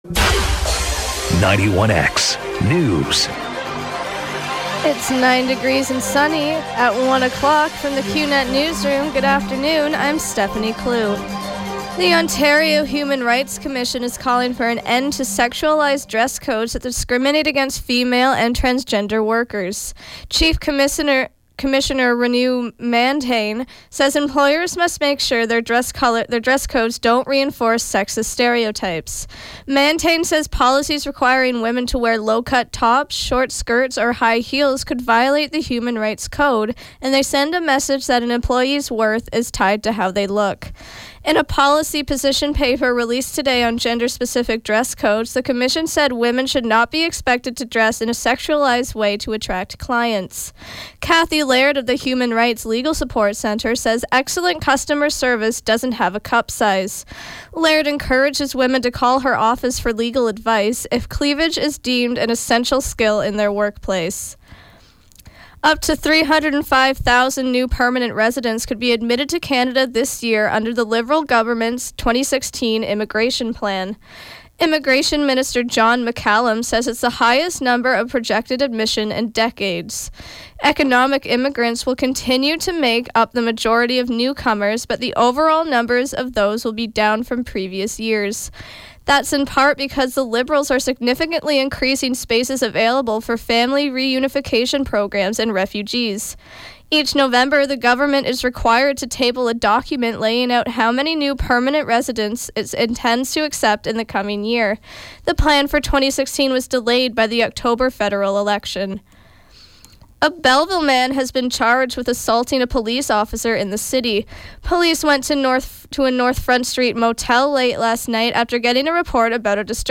91X Newscast – Tuesday, March 8, 2016, 1 p.m.